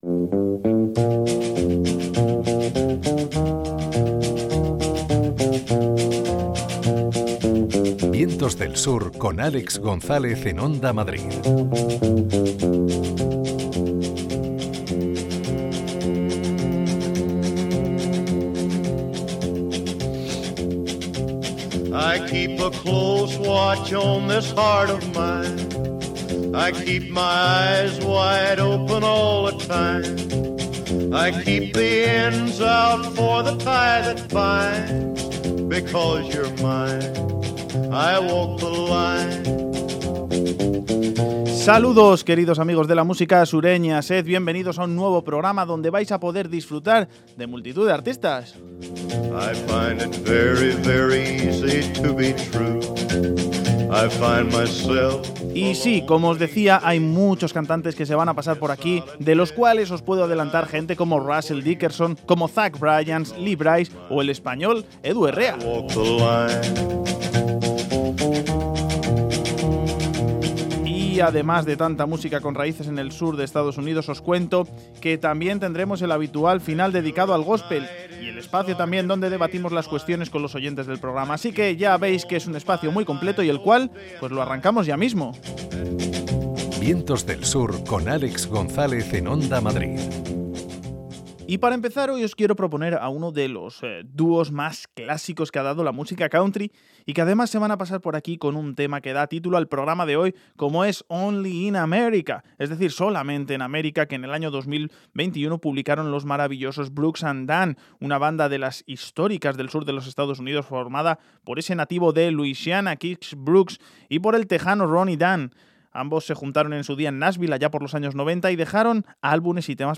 hace un repaso musical, da espacio a las cuestiones de los oyentes y acaba con un tema de gospel.